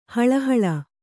♪ haḷa haḷa